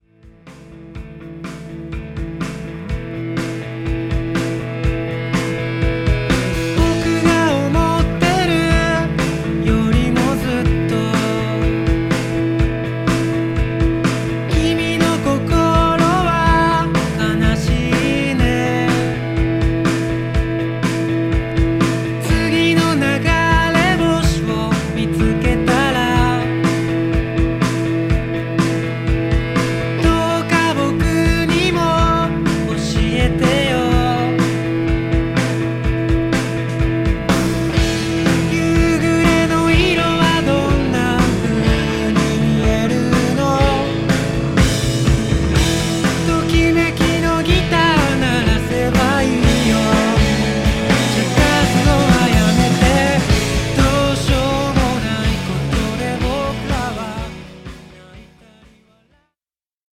京都の男女混成オルタナ・ポップ・バンド。